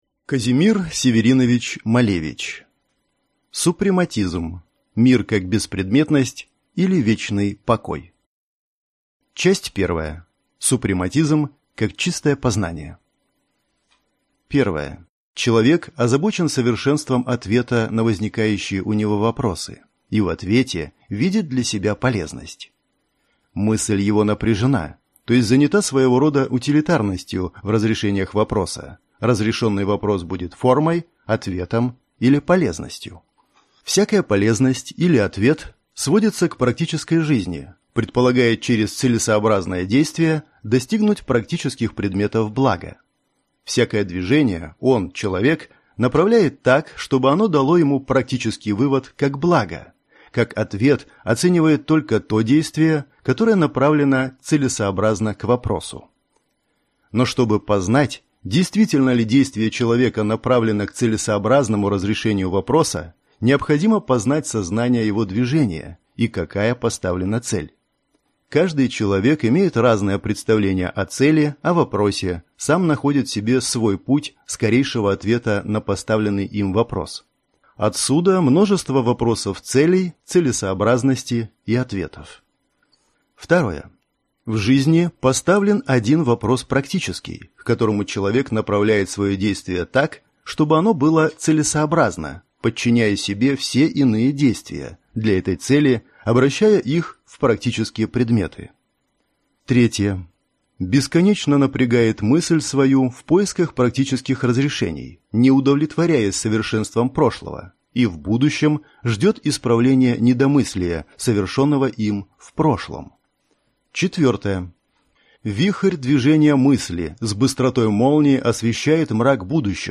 Аудиокнига Супрематизм. Мир как беспредметность, или Вечный покой | Библиотека аудиокниг